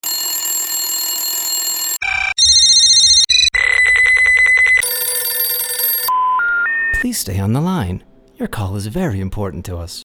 Caller - Reality Bytes